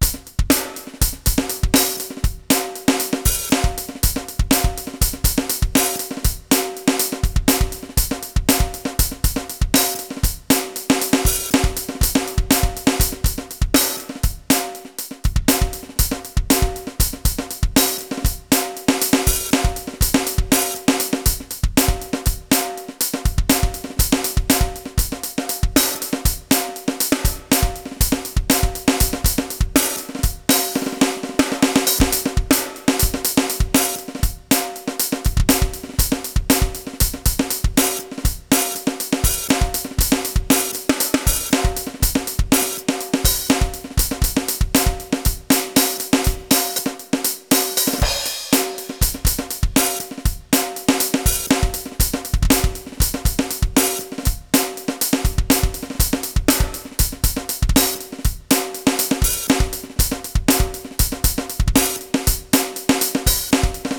high_happy_beat_120.WAV